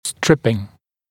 [‘strɪpɪŋ][‘стрипин]сепарация, сошлифовывание боковых поверхностей зубов